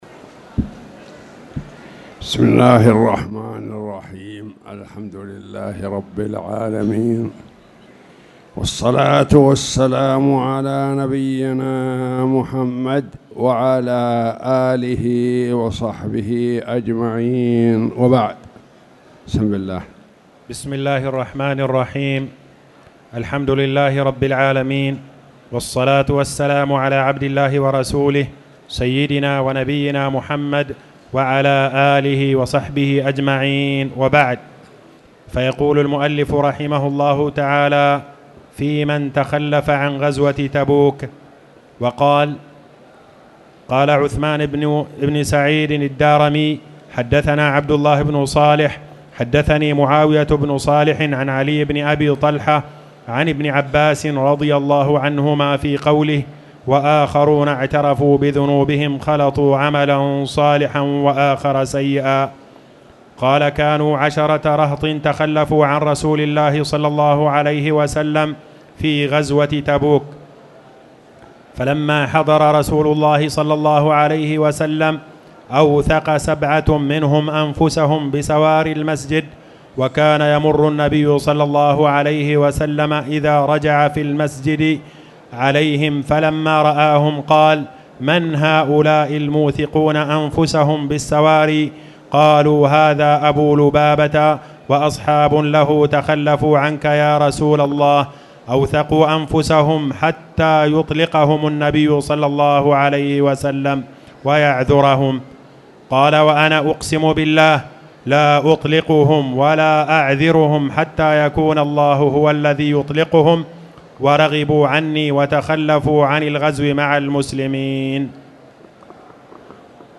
تاريخ النشر ٣ ربيع الأول ١٤٣٨ هـ المكان: المسجد الحرام الشيخ